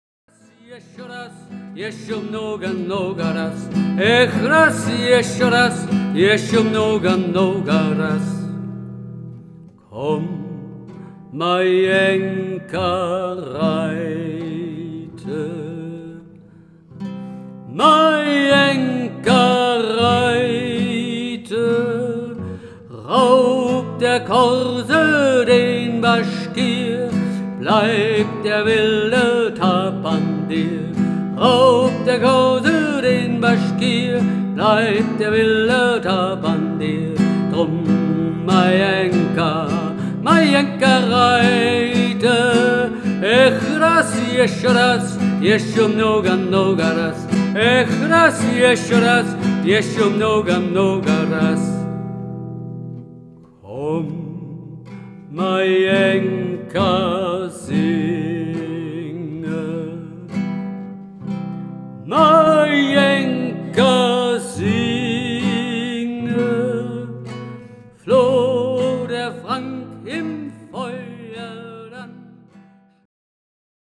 (Russische Fassung)
das "ch" in "Ech" wird wie im Wort "ach" ausgesprochen